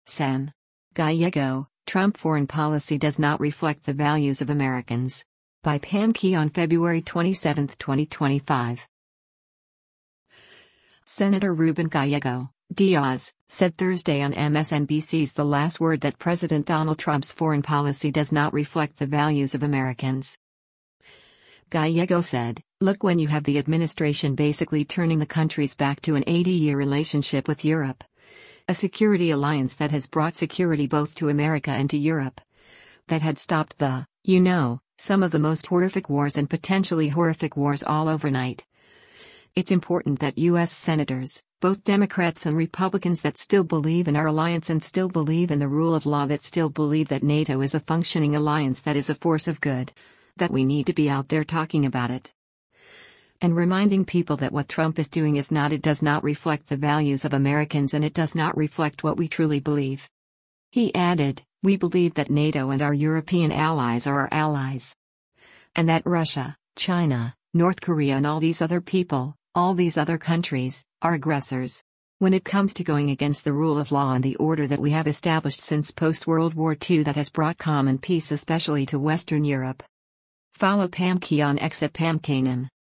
Senator Ruben Gallego (D-AZ) said Thursday on MSNBC’s “The Last Word” that President Donald Trump’s foreign policy “does not reflect the values of Americans.”